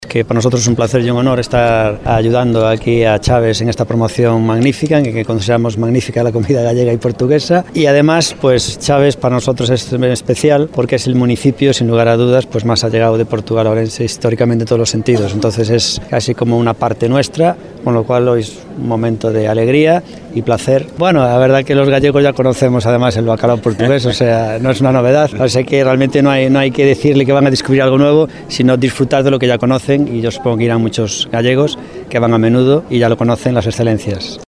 O alcalde de Ourense, Gonzalo Péres Jácome lembra a relação histórica entre Chaves e Ourense fazendo todo o sentido esta apresentação na sua cidade.